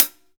Perc (199).wav